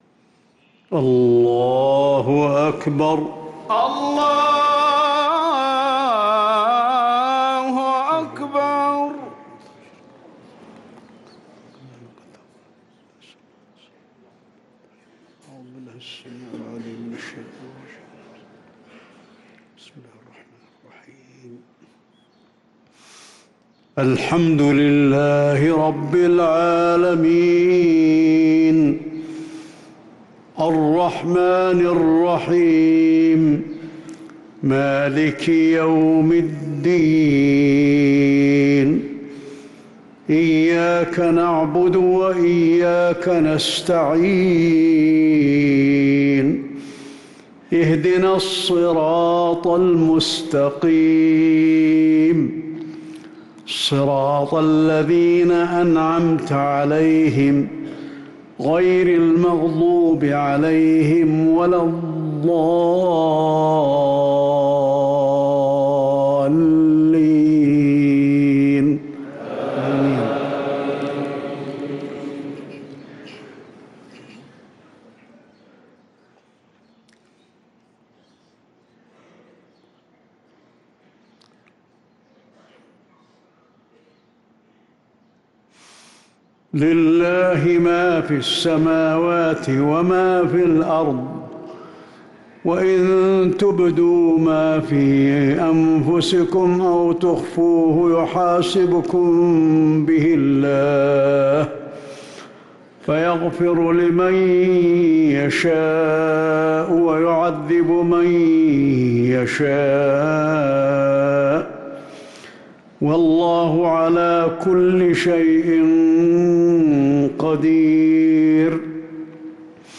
صلاة المغرب للقارئ علي الحذيفي 28 رجب 1444 هـ